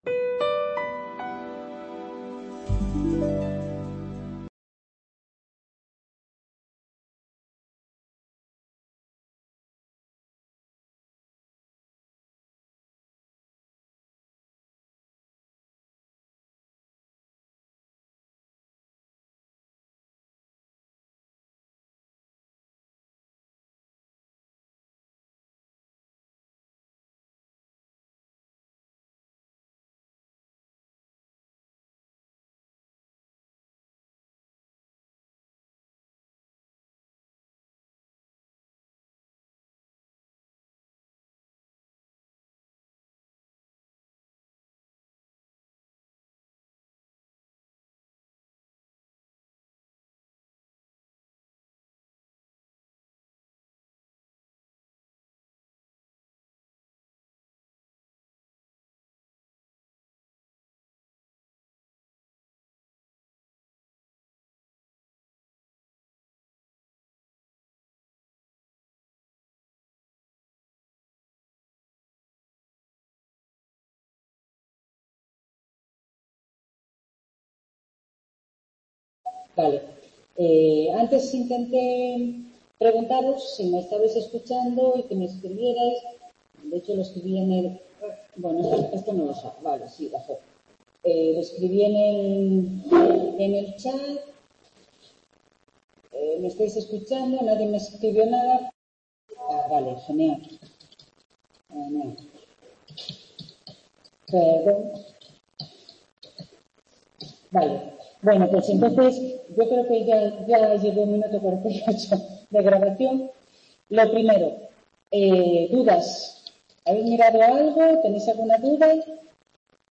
Tutoría 9/10/23 Microeconomía 2º ADE | Repositorio Digital